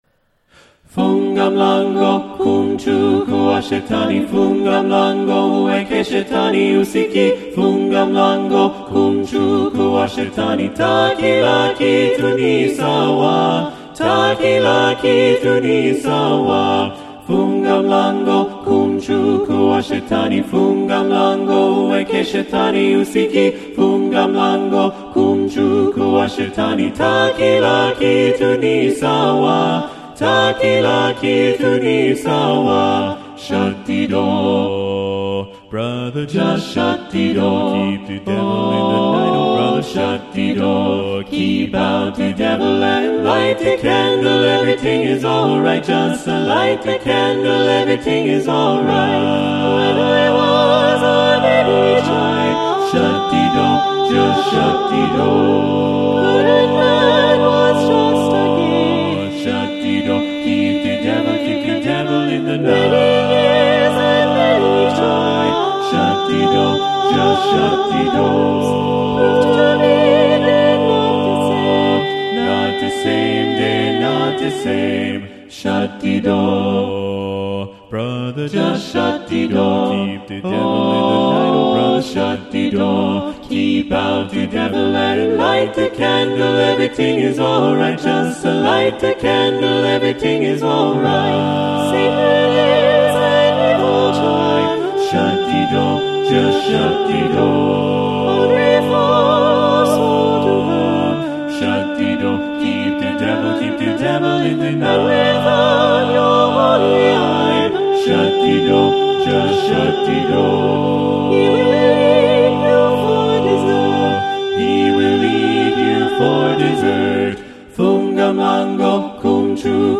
Composer: Spiritual
Voicing: SATB a cappella